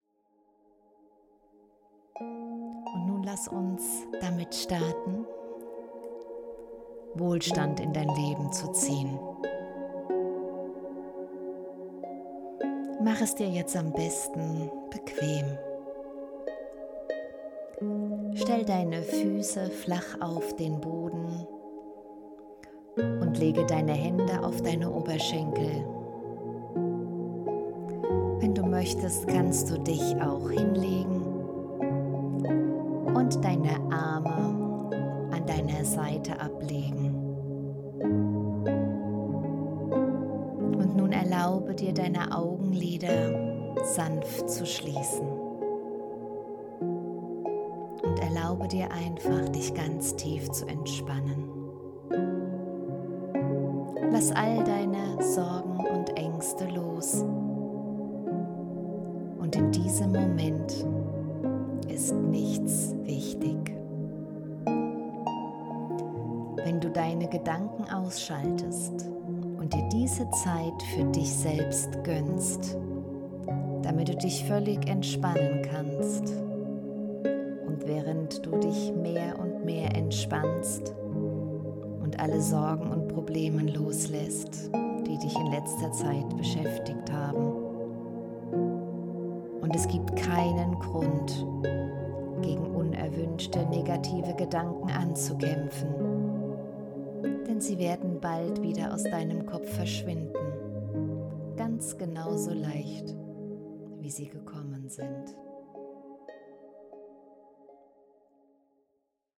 Hypnose Installation Fülle und Reichtum
Hier ist eine kleine Hörprobe von über einer Minute dieser Hypnose
Hoerprobe-H-011-Hypnose-Installation-des-Fuelle-und-Reichtumsprogramm.mp3